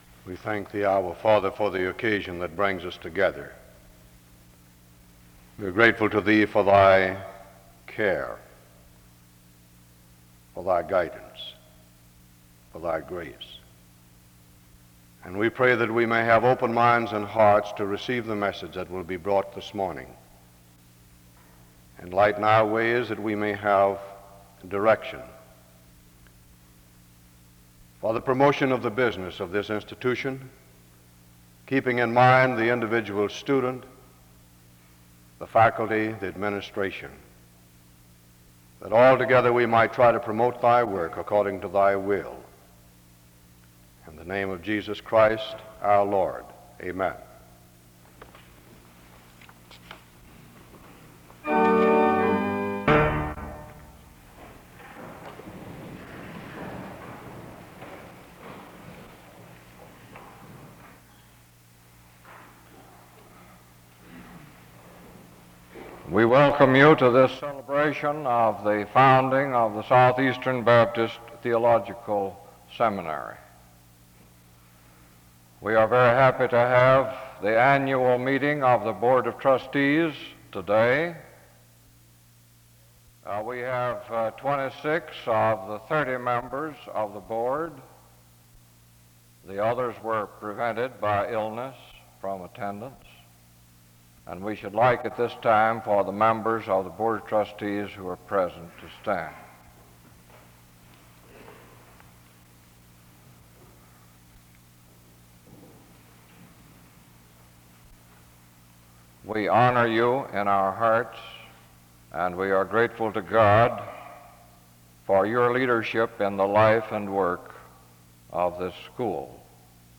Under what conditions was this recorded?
The service starts with a word of prayer from 0:00-0:47. The board of trustees are acknowledged from 1:06-2:21. The speaker is introduced from 2:22-6:15. A closing prayer is offered from 43:15-44:14.